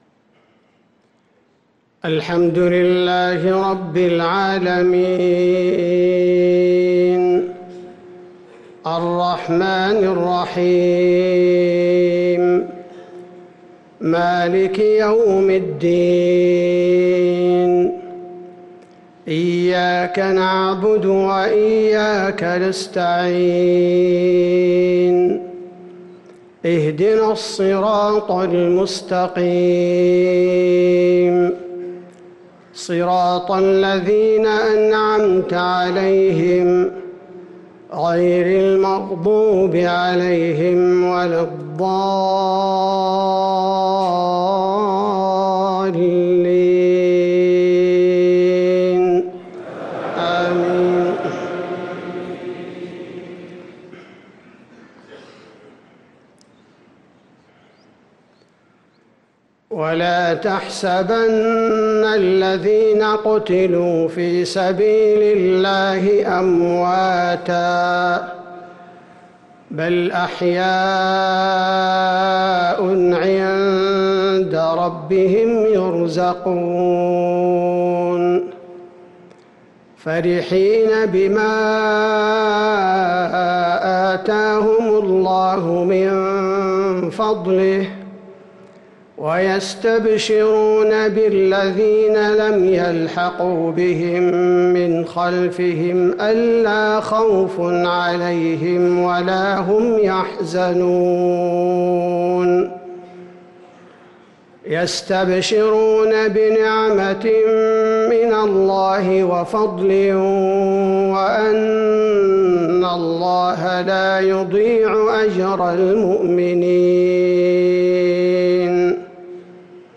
صلاة المغرب للقارئ عبدالباري الثبيتي 28 ربيع الأول 1445 هـ